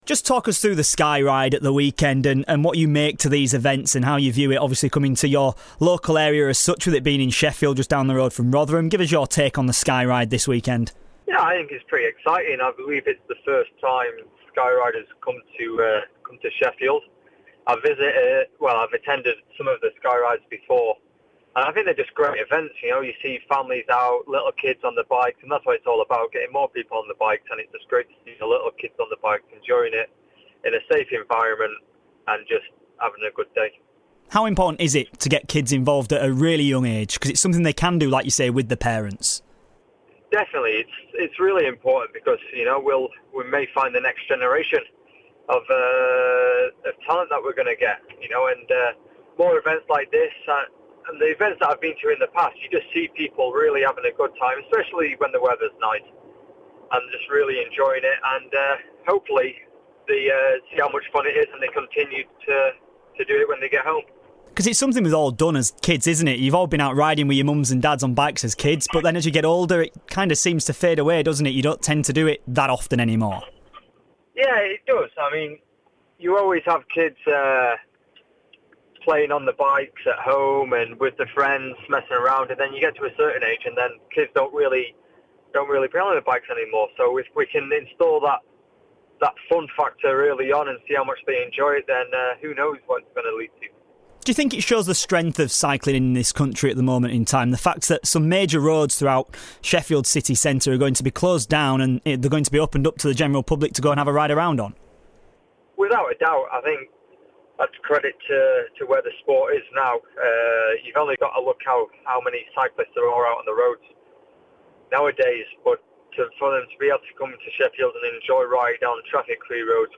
INTERVIEW: Rotherham cyclist Ben Swift on this weekends Sky Ride in Sheffield and his return to action.